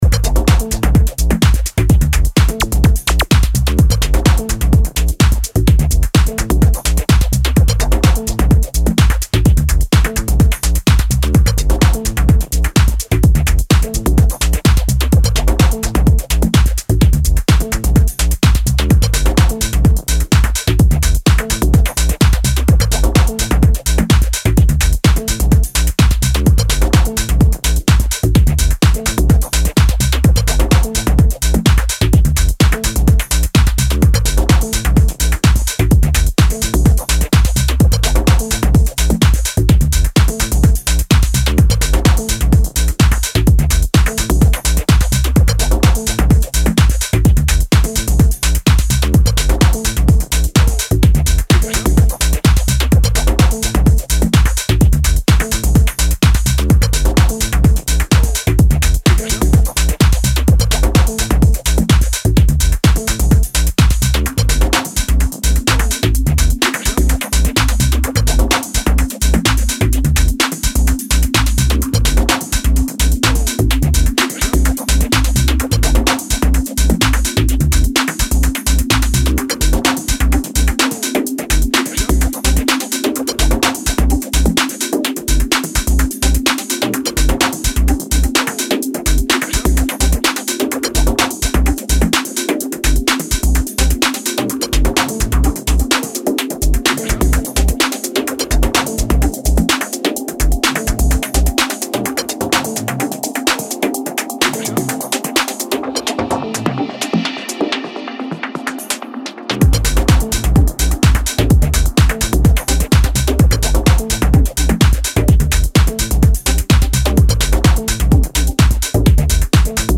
signature energetic approach
two full power, groove induced cuts